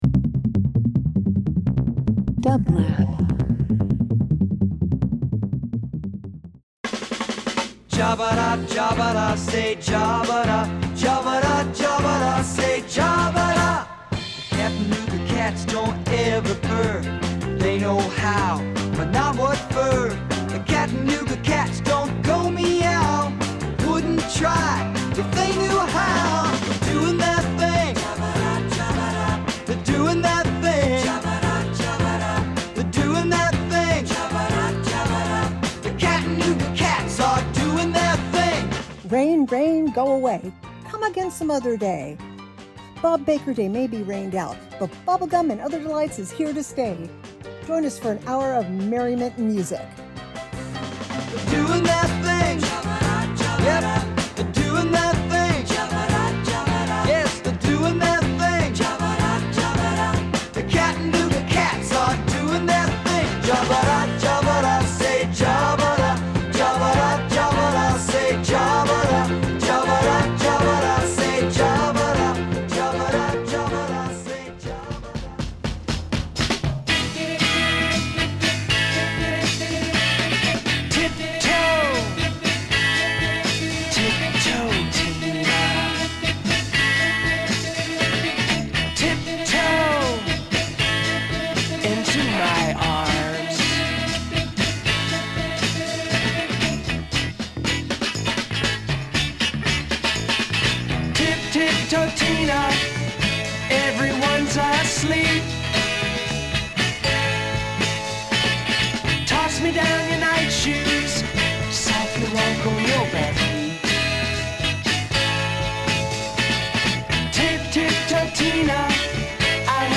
These rare pop confections may ruin your appetite.
Garage Rock